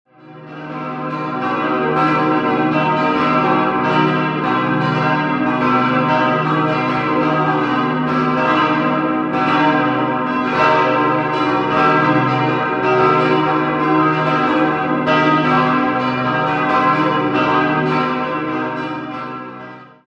Das moderne Kreuzbild im Altarraum kam erst um das Jahr 2000 hinzu. 5-stimmiges erweitertes C-Moll-Geläute: c'-es'-g'-b'-c'' Alle Glocken wurden im Jahr 1965 von der Firma Eschmann gegossen.